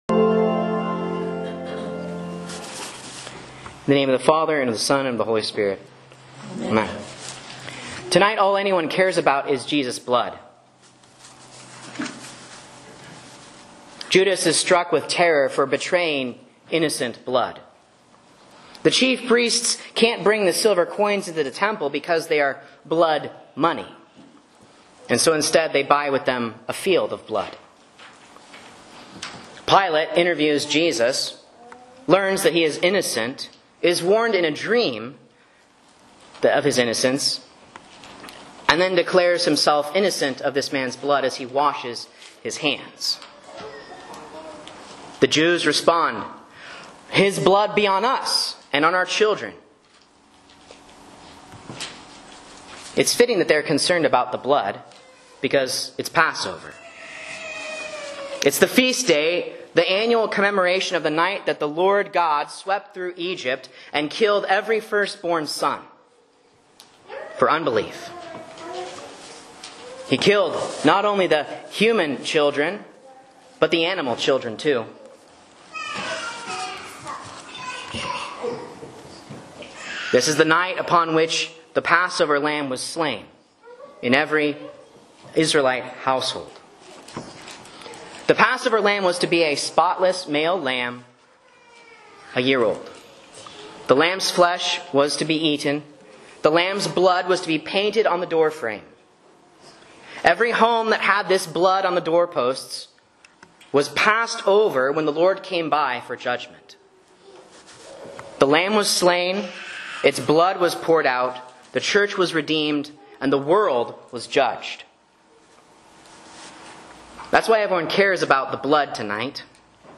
A Sermon on Matthew 27:1-31 for Lent Midweek